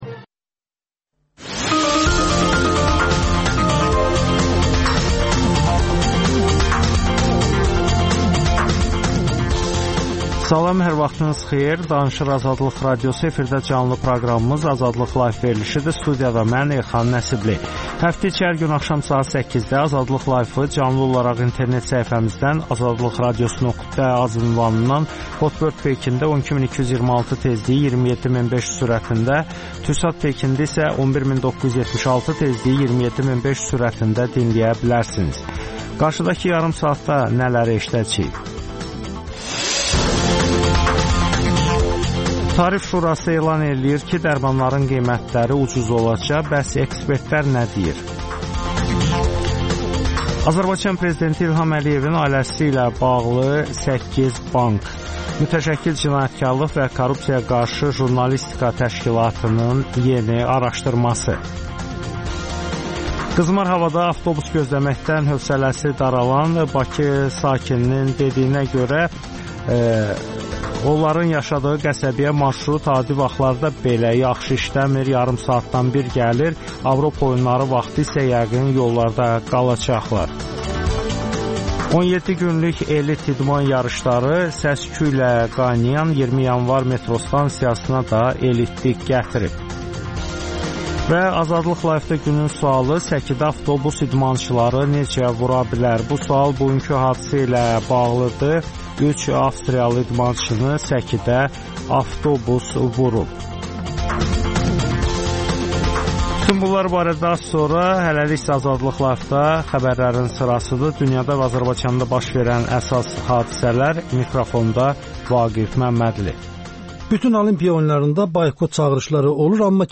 Azərbaycanda və dünyda baş verən hadisələrin ətraflı analizi, təhlillər, müsahibələr.